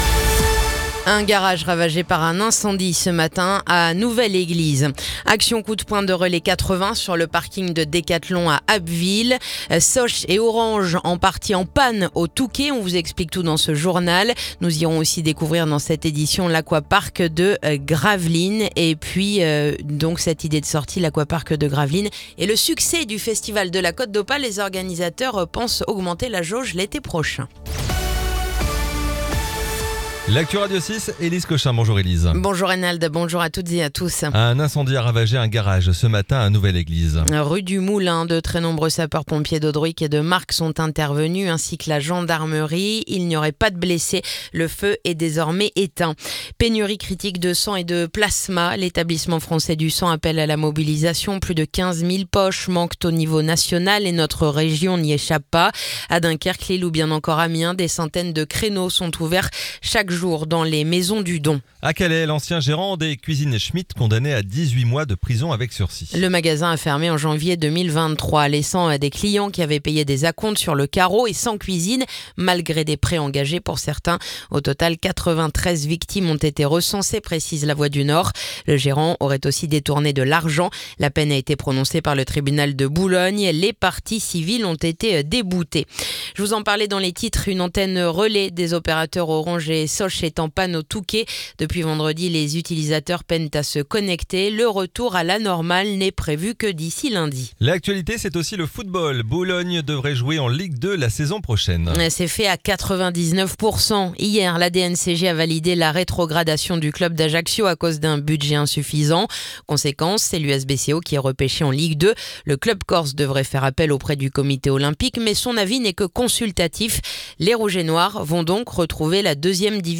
Le journal du mercredi 16 juillet